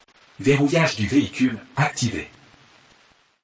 Tesla Lock Sound French Man
French male voice saying
(This is a lofi preview version. The downloadable version will be in full quality)
JM_Tesla-Lock_French_Man_Watermark.mp3